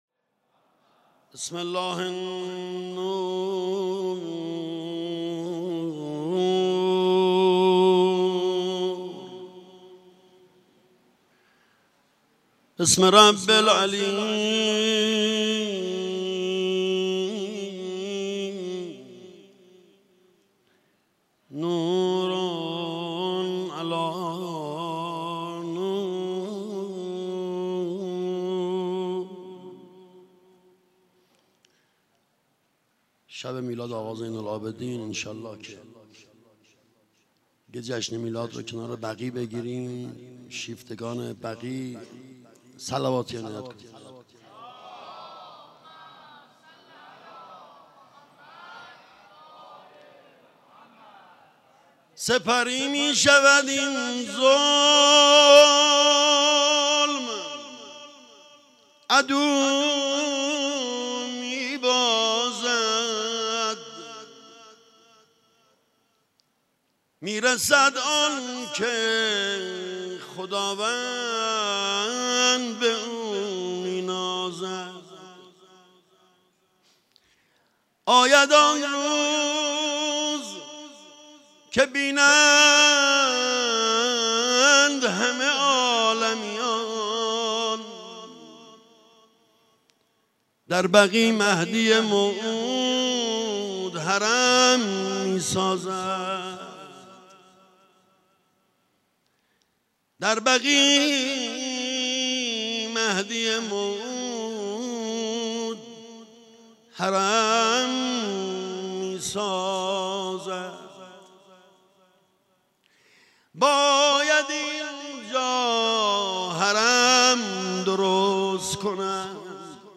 مداحی
در مسجد حضرت امیر(ع) برگزار شد.